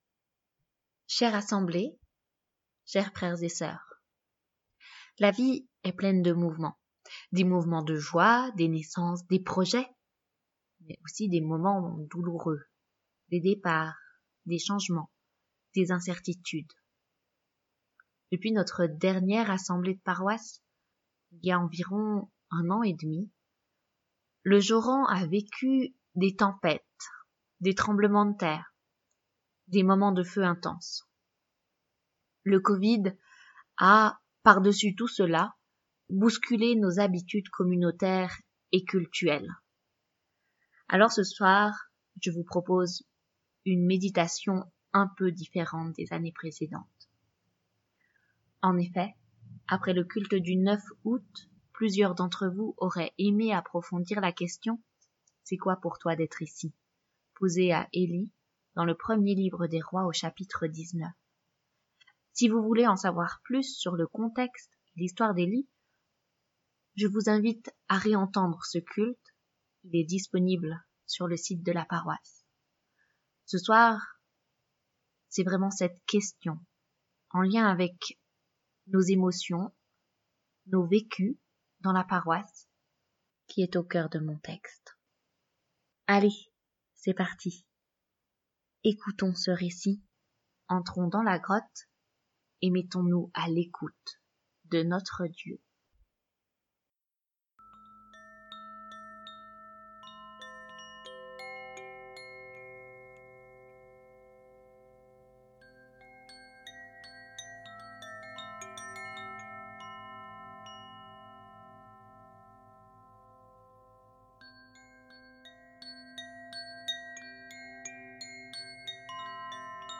Méditation pour l’assemblée de paroisse
Bienvenus en ce temple de Saint-Aubin pour l’assemblée de paroisse du Joran.
meditation-assemblee-de-paroisse.mp3